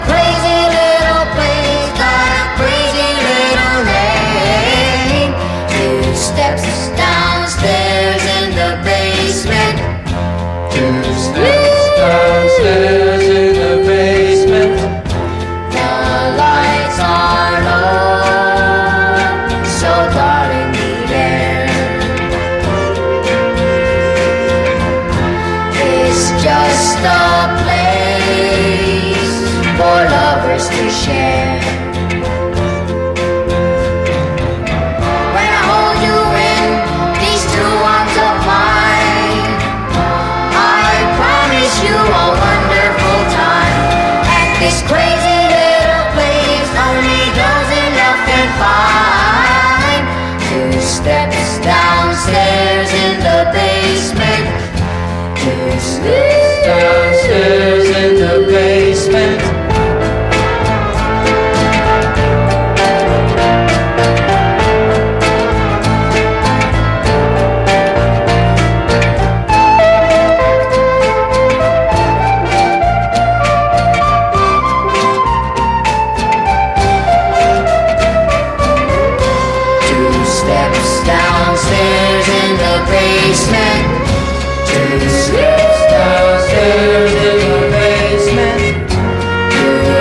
オブスキュアなコーラス自主盤！
自主盤ならではの屈託のない解放感に満ち溢れた空気が素晴らしいです。